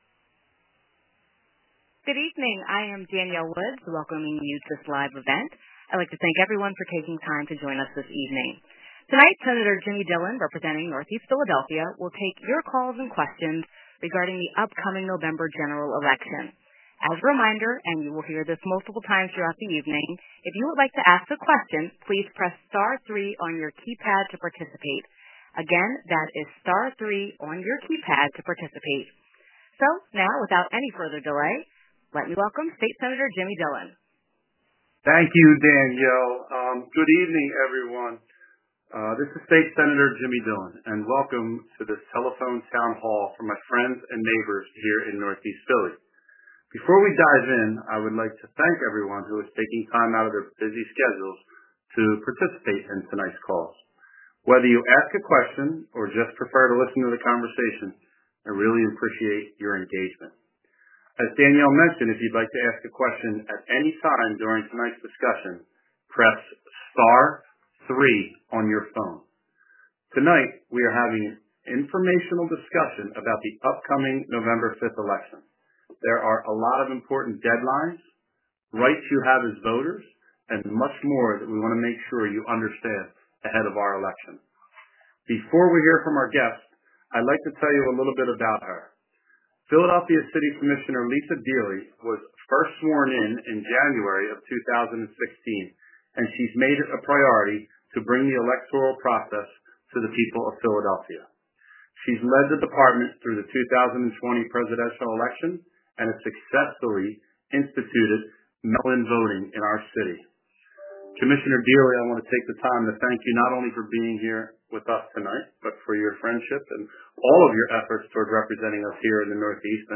Telephone Town Hall - I-95 Collapse & Restoration
June 26, 2023 | Senator Jimmy Dillon, Councilmember Mike Driscoll and State Representative Pat Gallagher hosted a Telephone Town Hall to discuss the I-95 Collapse & Restoration.